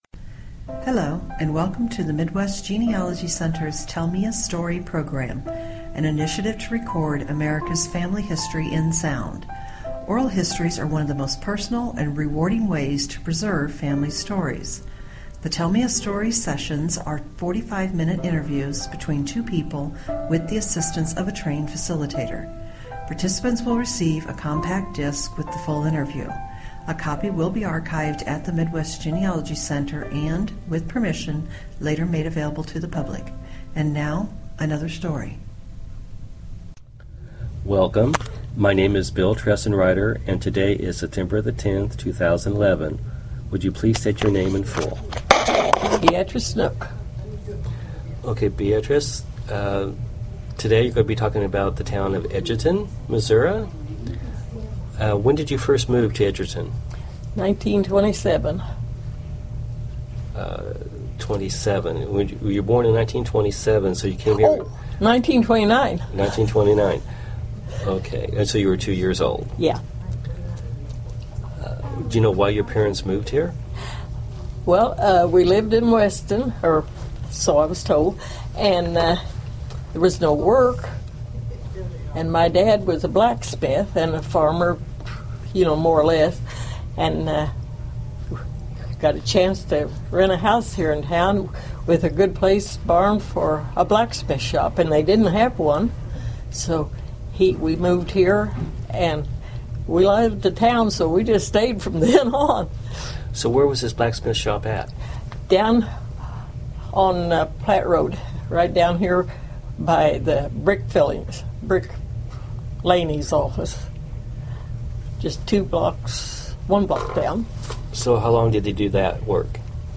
Edgerton, Missouri Pioneer Days 2011